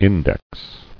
[in·dex]